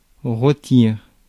Ääntäminen
France, Paris: IPA: [ʁo.tiʁ]